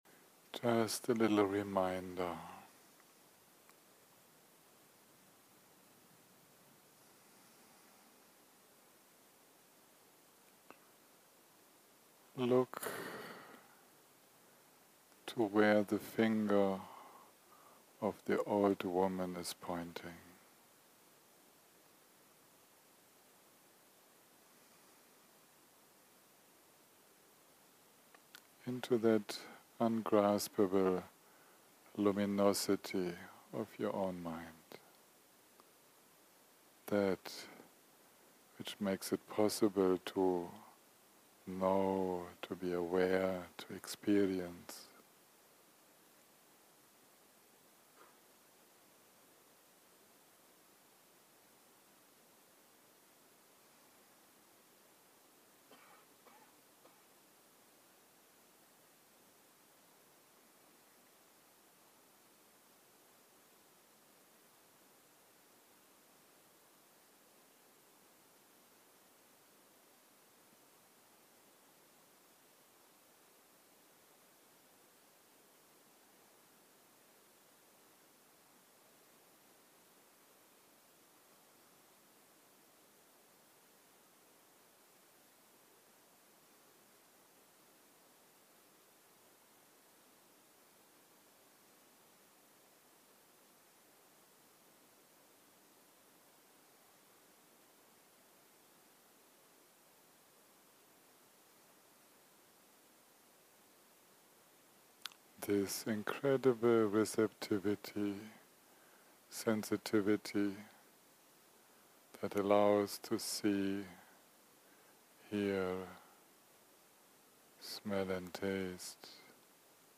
יום 1 - הקלטה 3 - ערב - מדיטציה מונחית - Last sitting and mantra song
יום 1 - הקלטה 3 - ערב - מדיטציה מונחית - Last sitting and mantra song 0:00 0:00 סוג ההקלטה: Dharma type: Guided meditation שפת ההקלטה: Dharma talk language: English